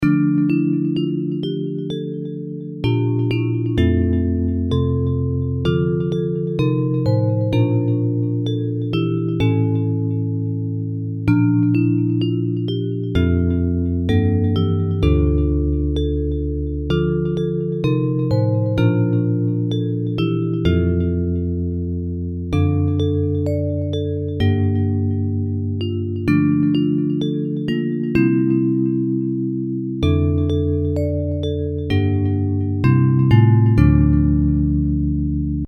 Bells Version
Music by: French carol melody;